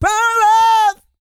E-GOSPEL 130.wav